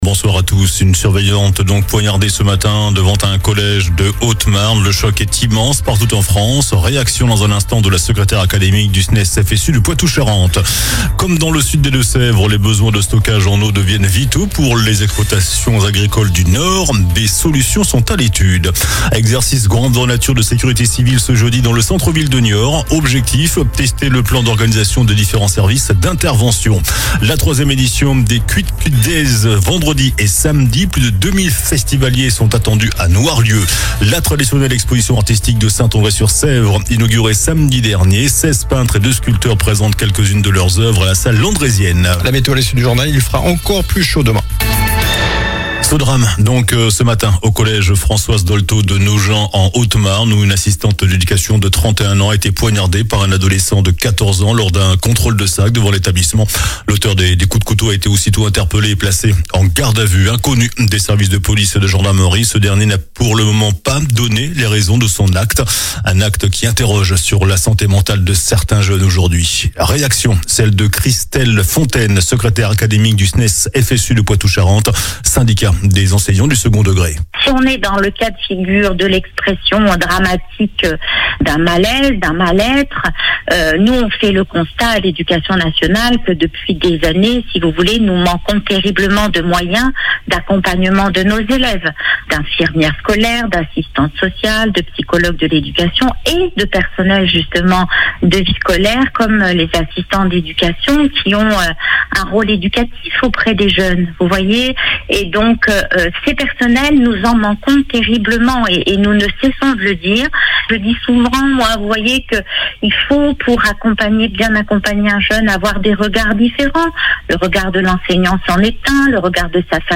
JOURNAL DU MARDI 10 JUIN ( SOIR )